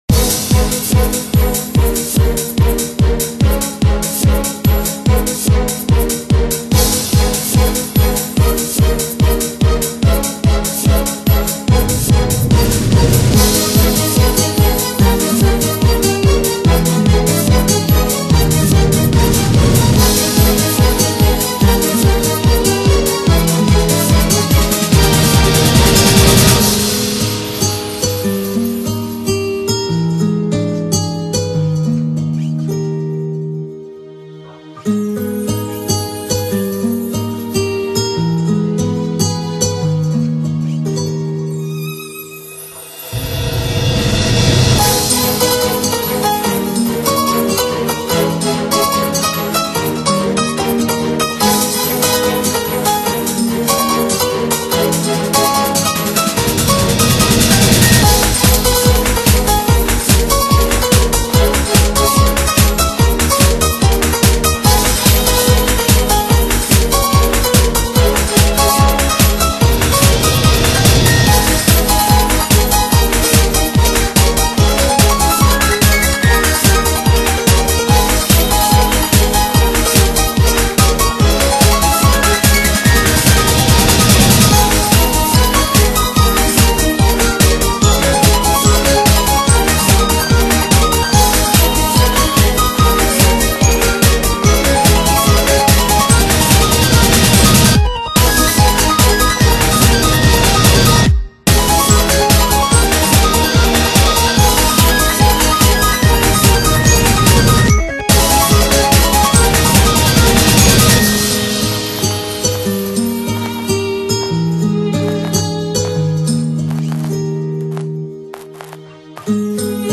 퓨전은 아니지만 피아노와 기타선율이 멋져 올려봤습니다.